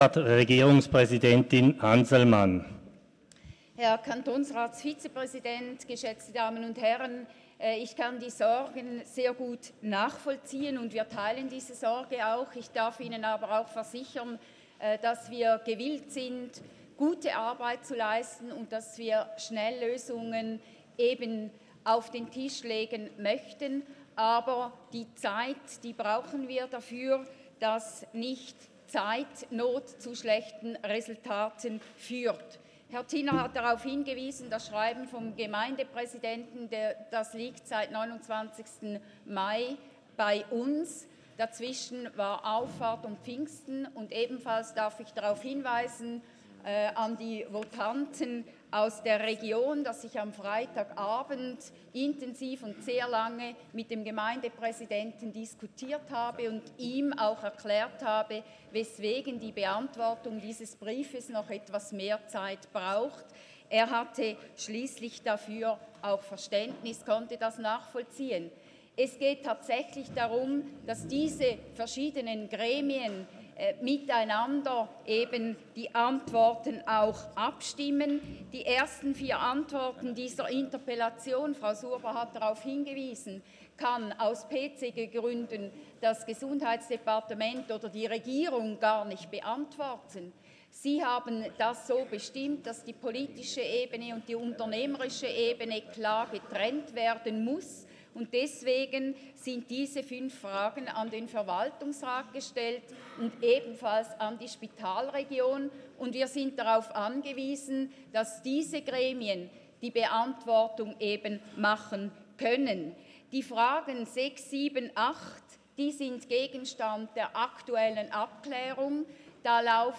Regierungspräsidentin:
Session des Kantonsrates vom 11. bis 13. Juni 2019